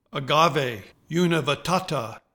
Pronounciation:
A-GA-ve u-ni-vit-TA-ta